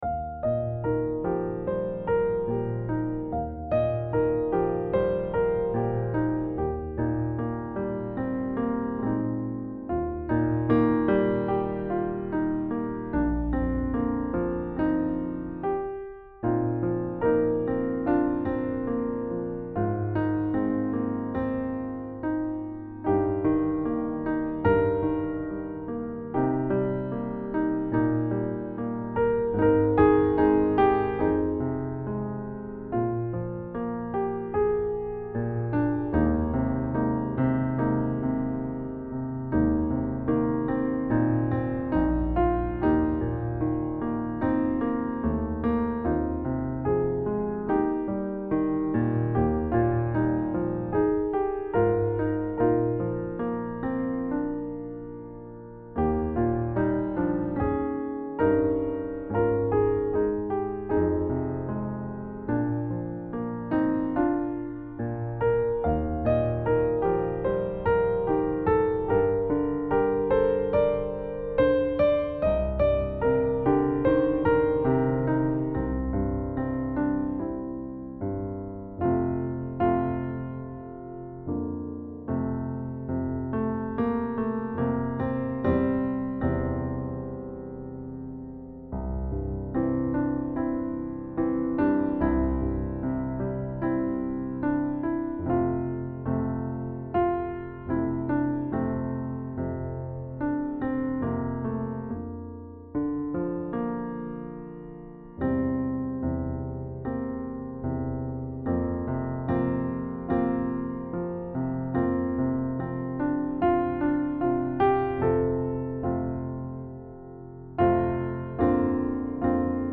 SATB and piano.